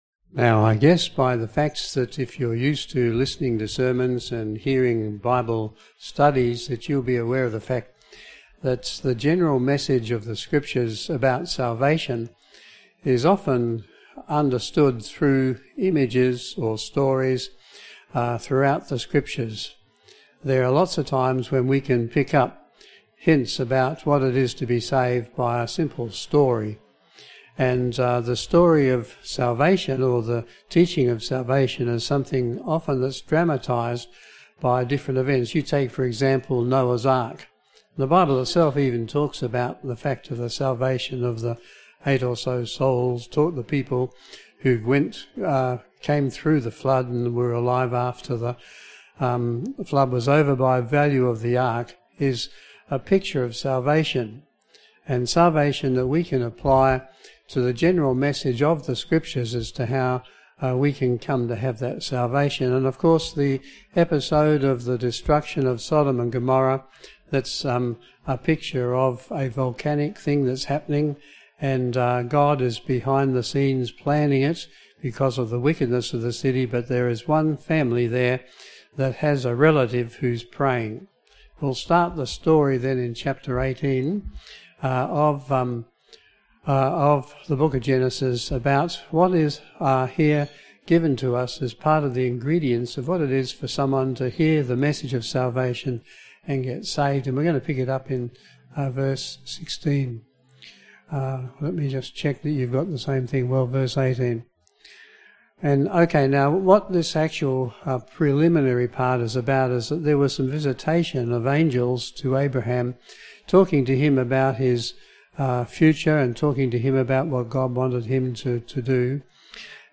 Service Type: PM Service